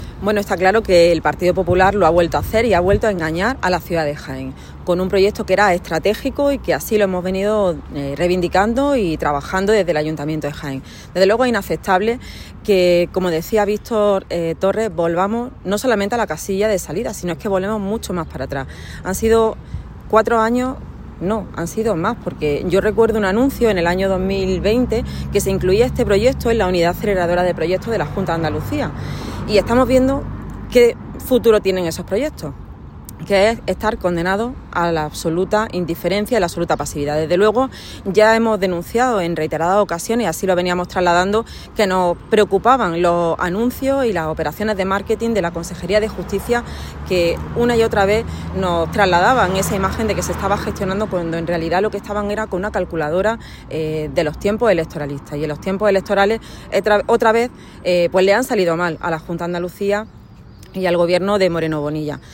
Por su parte, la viceportavoz del Grupo Socialista en el Ayuntamiento de Jaén, África Colomo, denunció que el PP “lo ha vuelto a hacer, ha vuelto a engañar a la ciudad”.